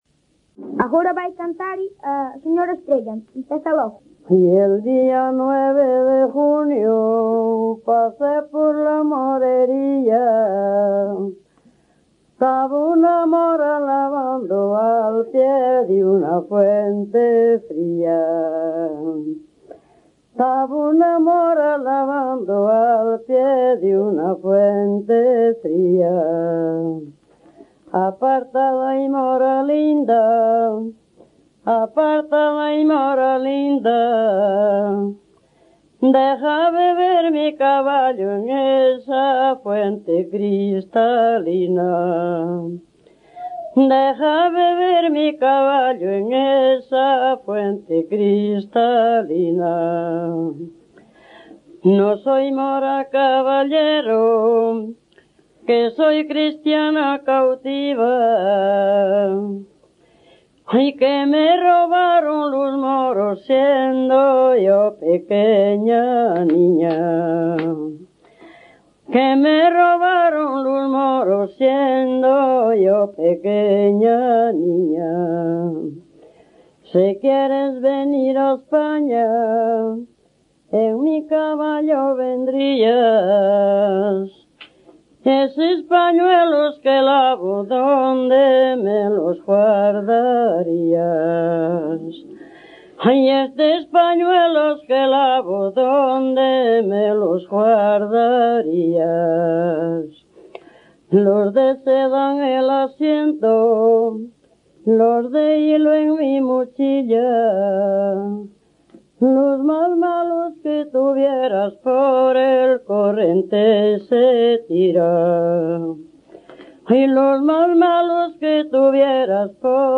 Áreas de coñecemento: LITERATURA E DITOS POPULARES > Cantos narrativos
Lugar de compilación: Mesía - Xanceda - Igrexa, A
Soporte orixinal: Casete
Instrumentación: Voz
Instrumentos: Voz feminina
Escala: 1 b2 b3 4 5 b6 b7 8
Modo de interpretación: Parlando